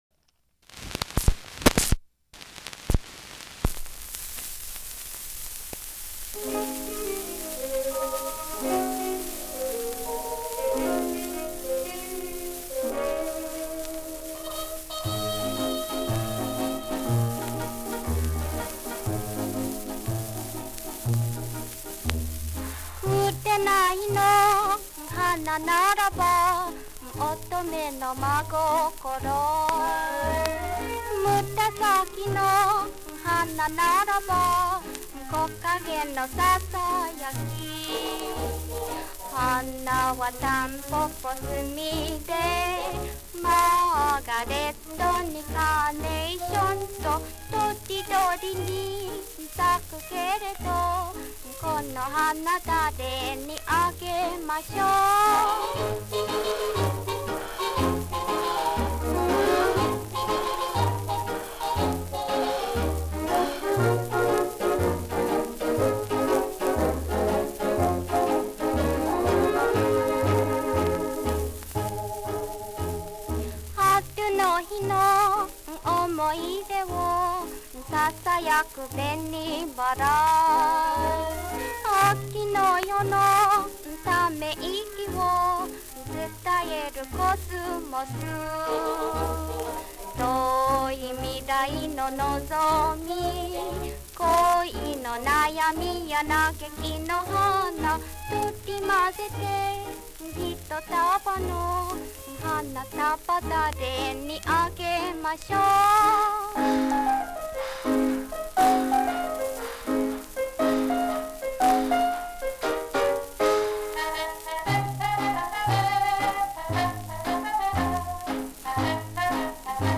(one speaker monaural